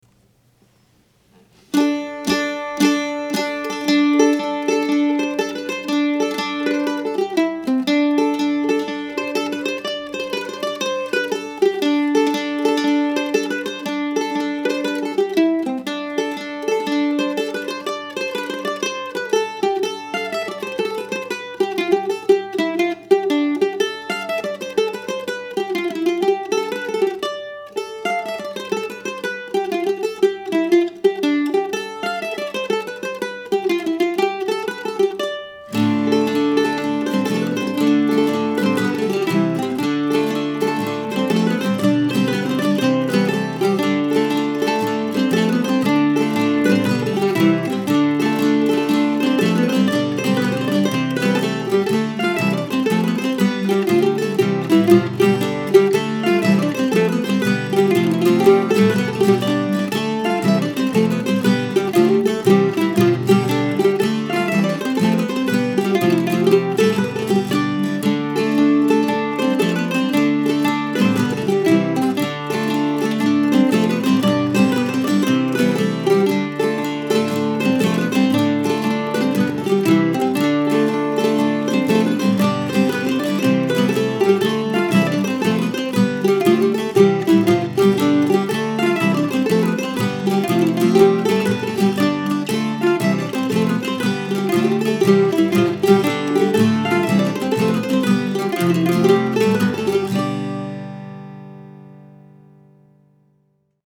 It had always been an E minor tune but I realized that it really wanted to be in D minor and that sealed the deal.
OrdainingTreesDm.mp3